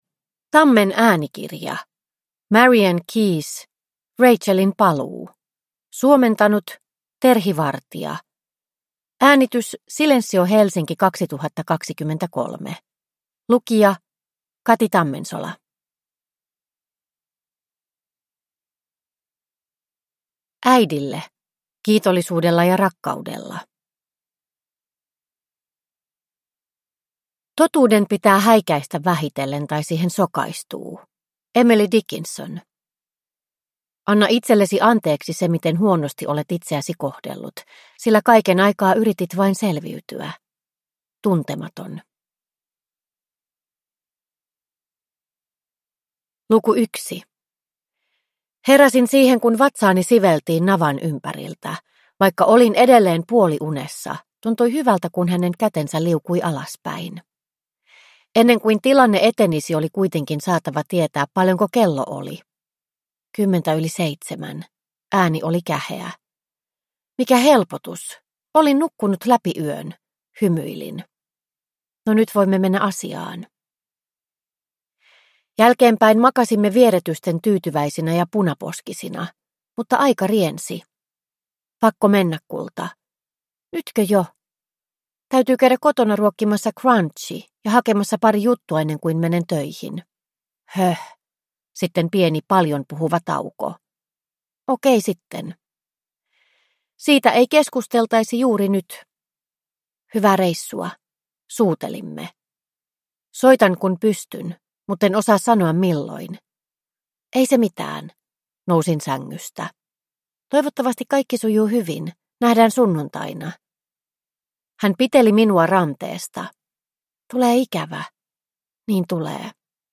Rachelin paluu – Ljudbok – Laddas ner